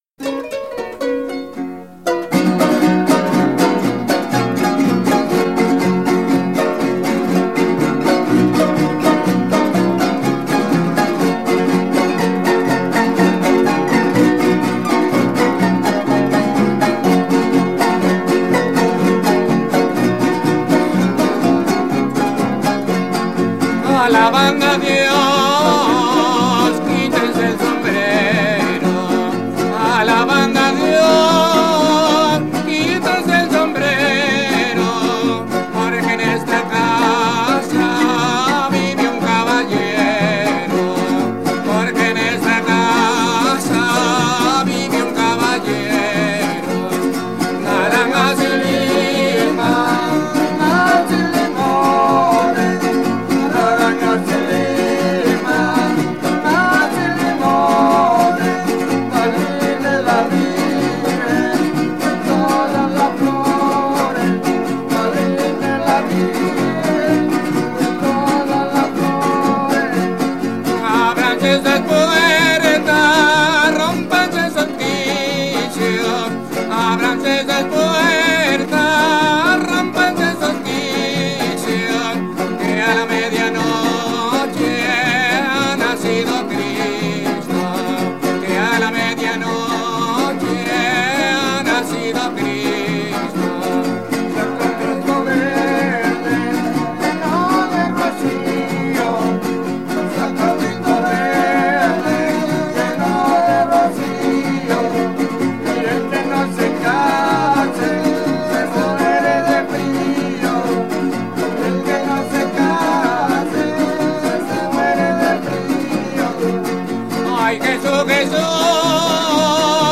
Keywords: música de navidad
Description: Varios intérpretes. Grabaciones de campo